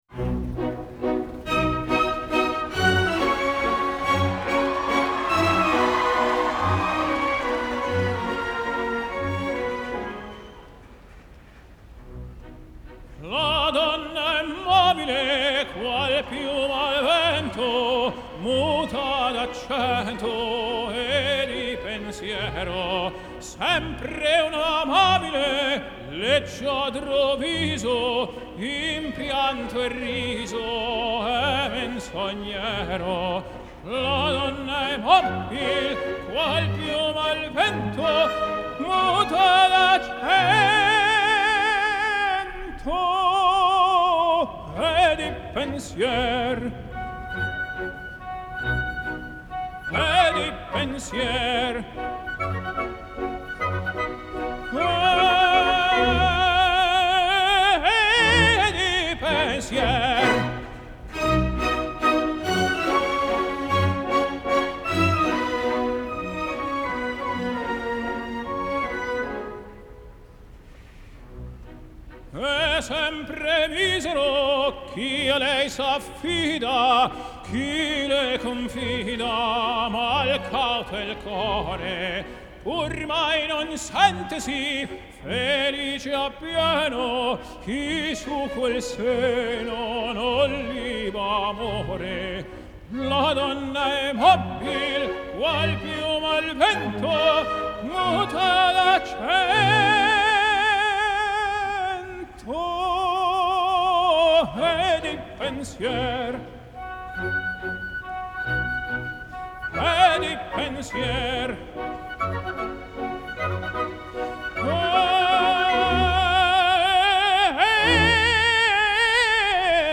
Genre : Classical
Live At Central Park, New York